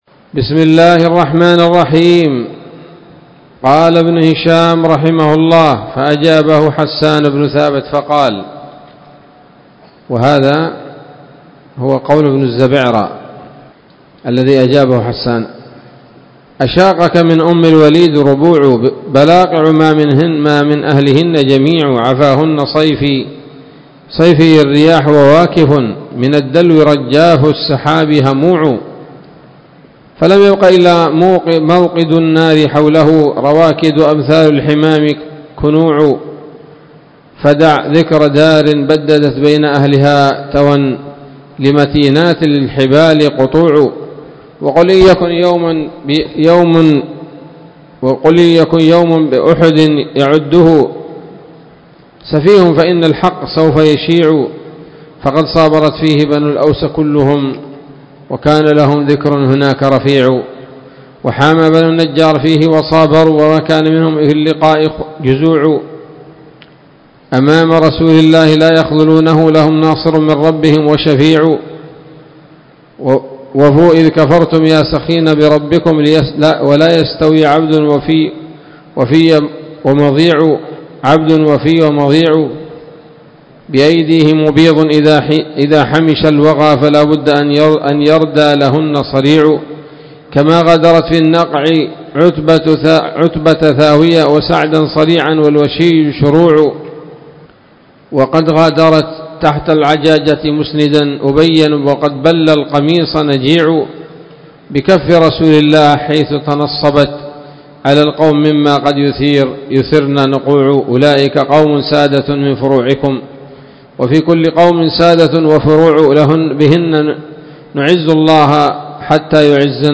الدرس التاسع والسبعون بعد المائة من التعليق على كتاب السيرة النبوية لابن هشام